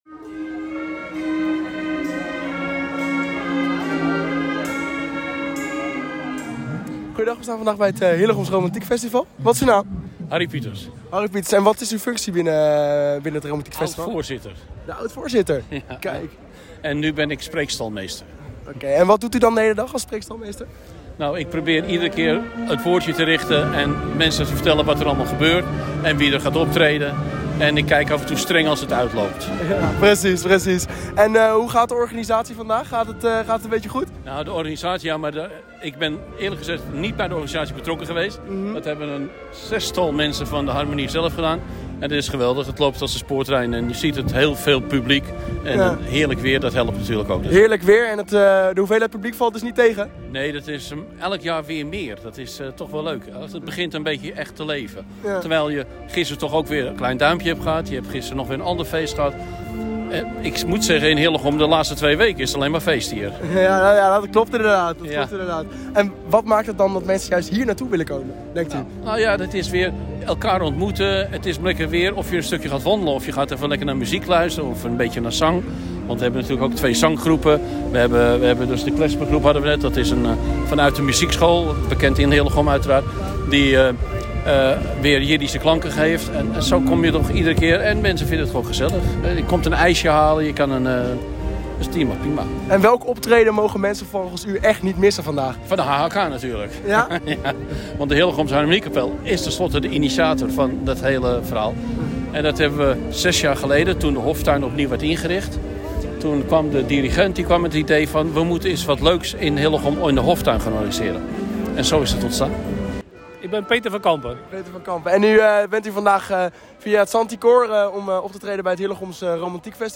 Hillegom – In de Houttuin in Hillegom werd zondag voor de vijfde keer het festival Hillegom Romantique georganiseerd.
interviews